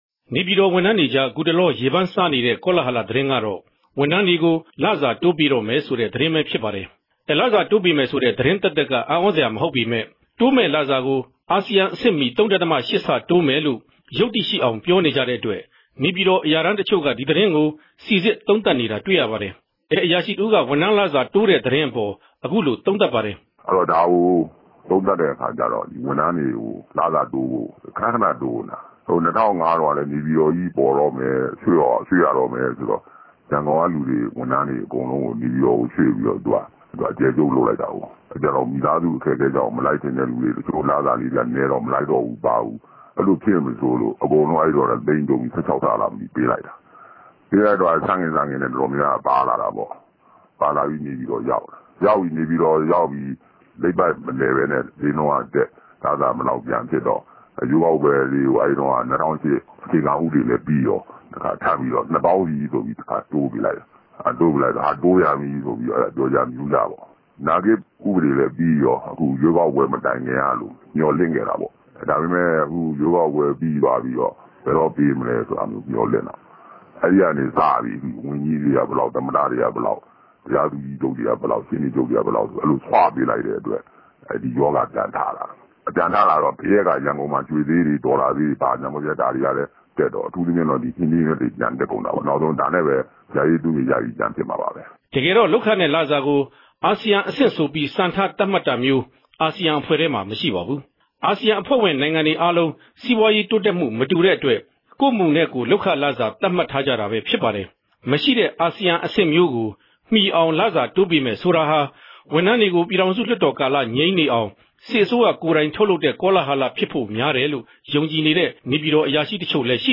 သုံးသပ်တင်ပြချက်။